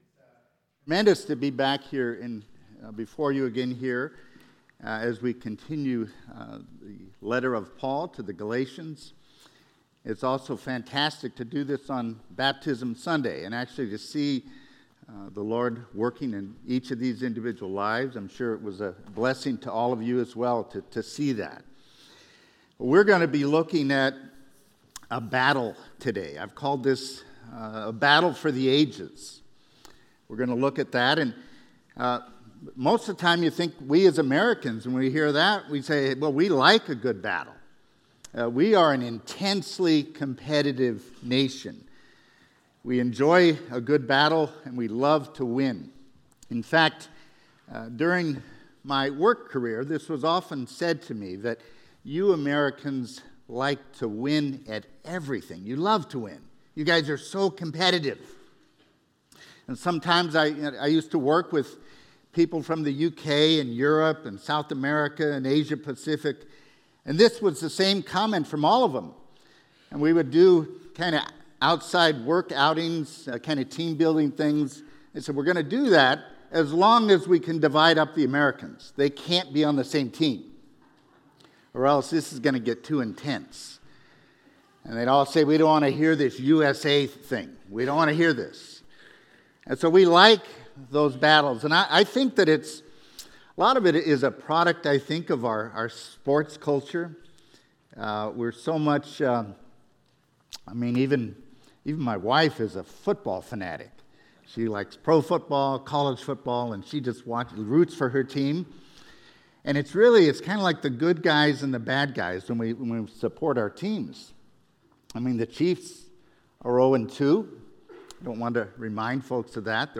A message from the series "Rescued by Grace."